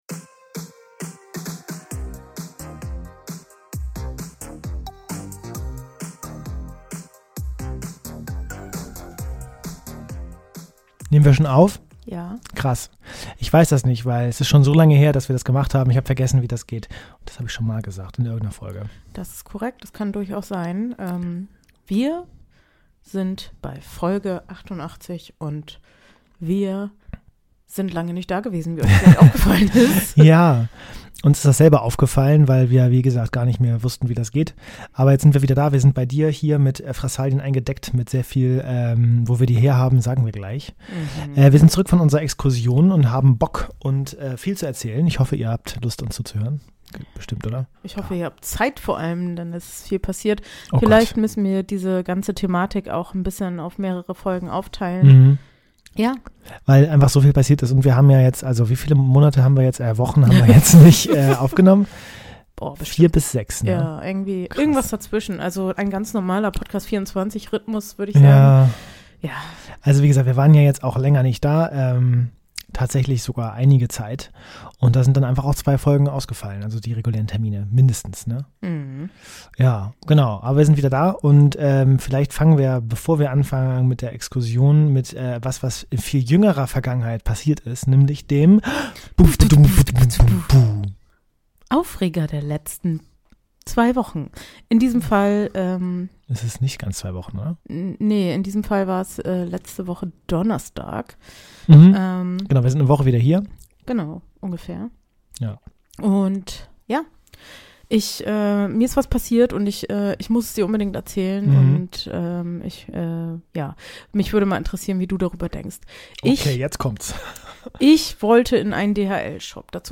Natürlich gibt es auch einen Aufreger. Achtung, bei der Aufnahme gab es technische Probleme, daher ist die Tonqualität teilweise etwas eingeschränkt.